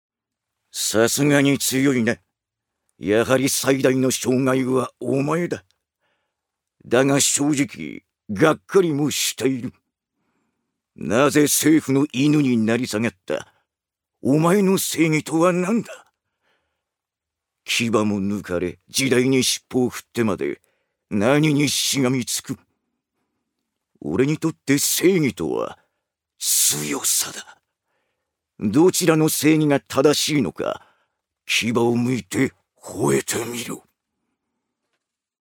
ジュニア：男性
セリフ１